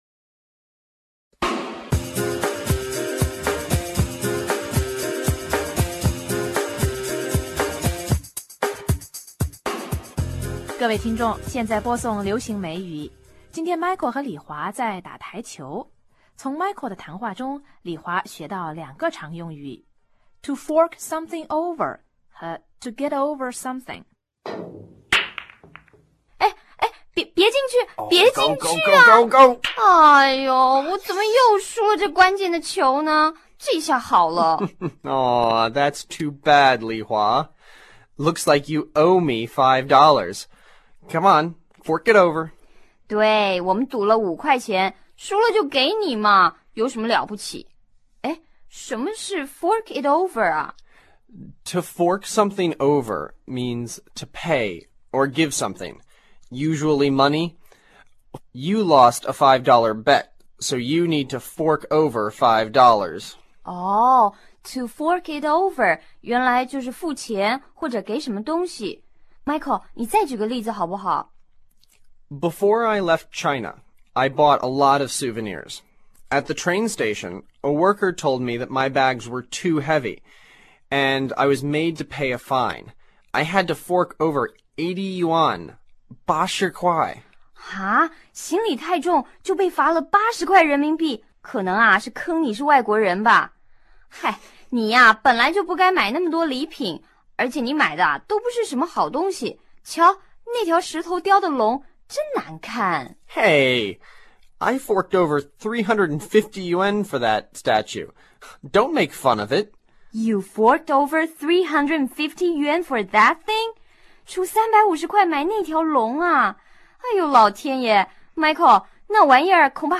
今天Michael和李华在打台球。